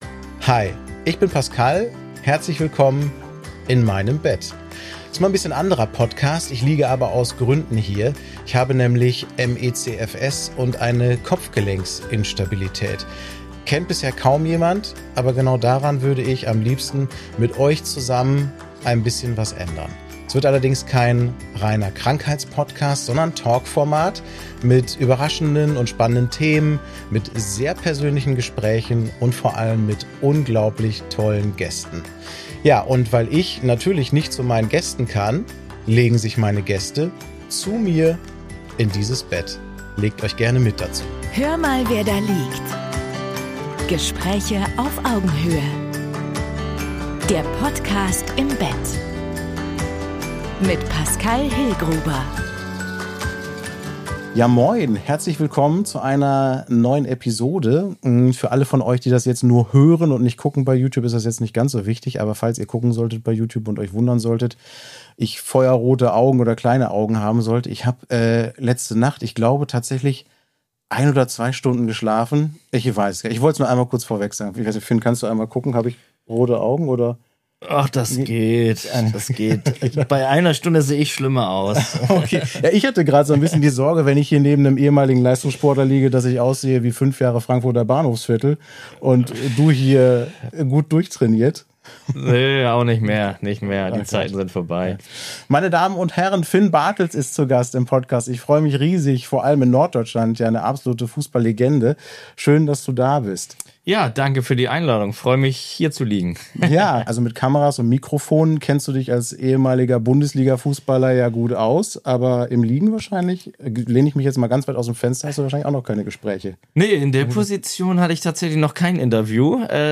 Ein ehrliches Gespräch über Bodenständigkeit, Verantwortung und das, was im Leben wirklich zählt.